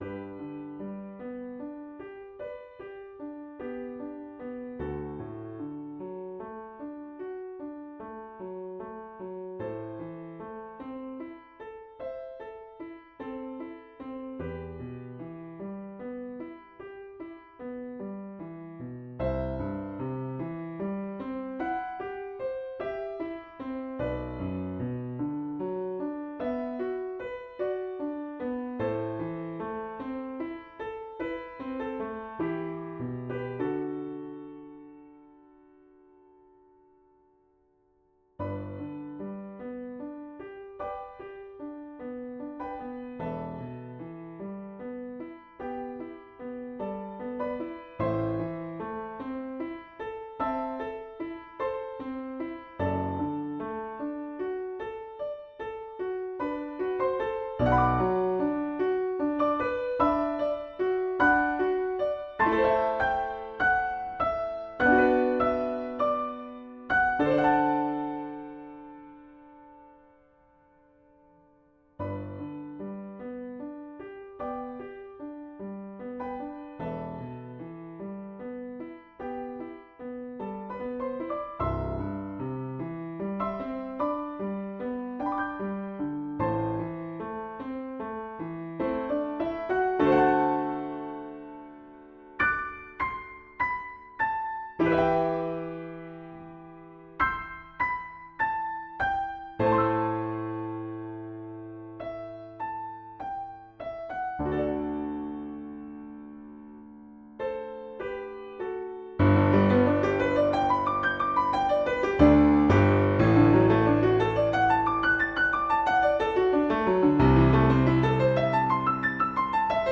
TEN ORIGINAL COMPOSITIONS FOR PIANO